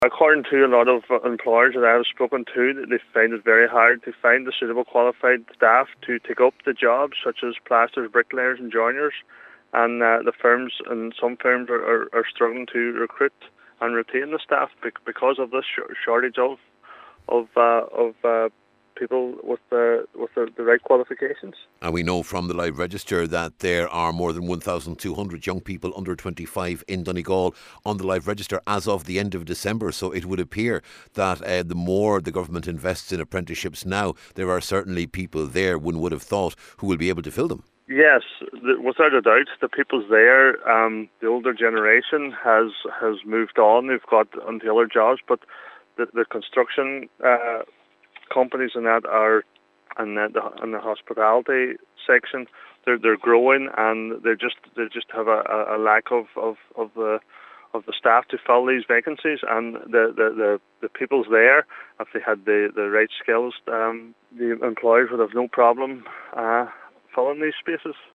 With 1,235 people under the age of 25 signing on the Live Register in Donegal at the end of December, Cllr Doherty says that’s not good enough, particularly as there are now shortages of skilled workers in some areas: